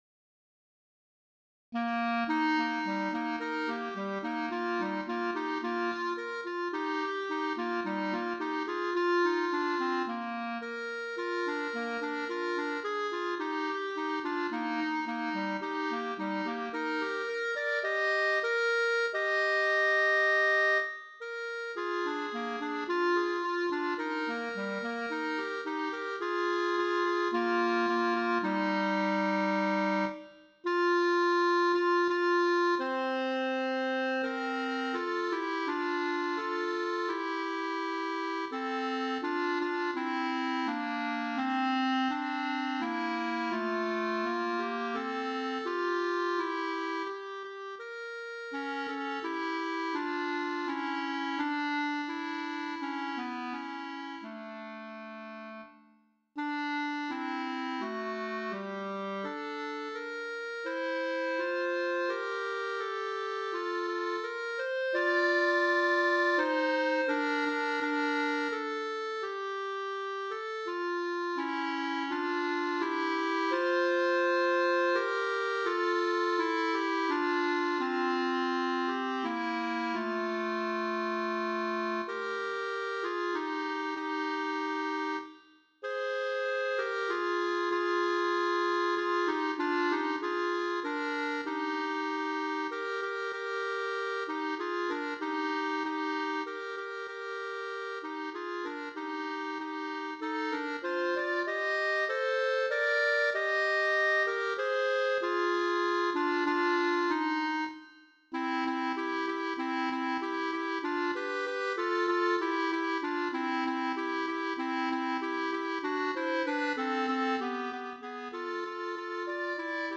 2 Clarinets